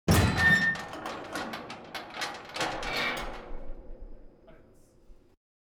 0335ec69c6 Divergent / mods / Soundscape Overhaul / gamedata / sounds / ambient / soundscape / underground / under_13.ogg 140 KiB (Stored with Git LFS) Raw History